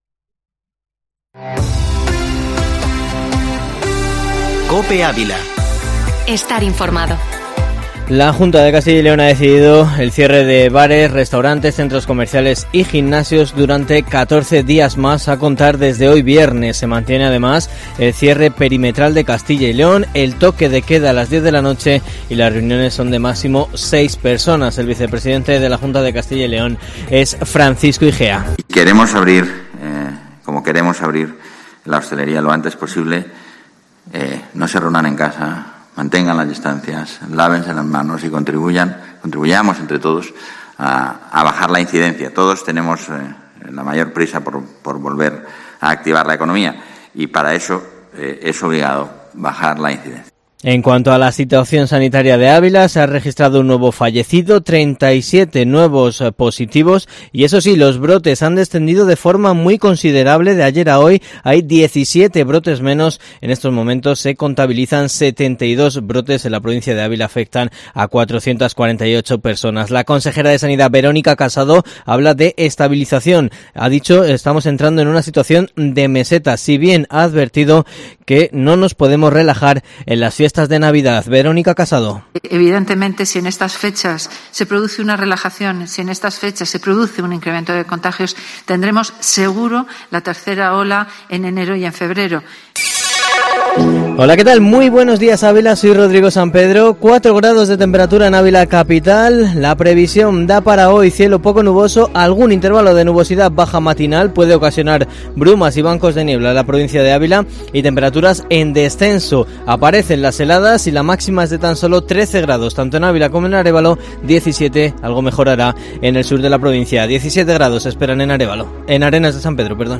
Informativo matinal Herrera en COPE Ávila 20/11/2020